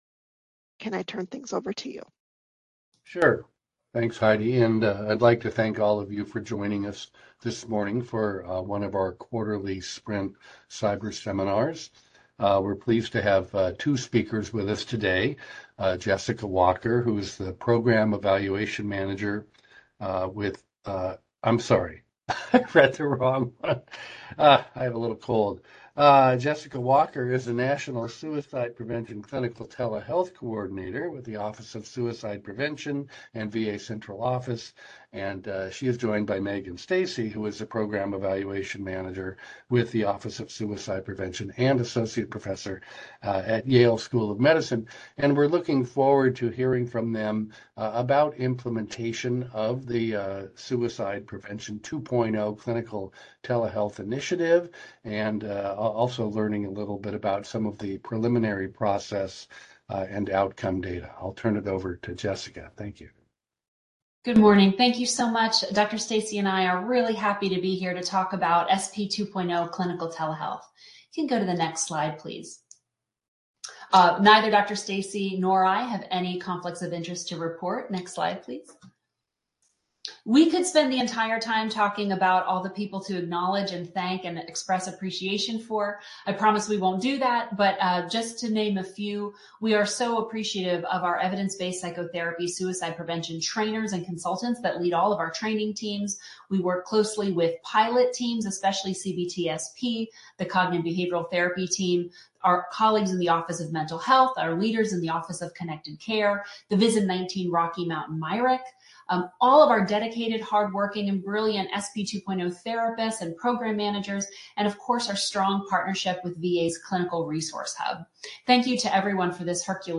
LP Seminar date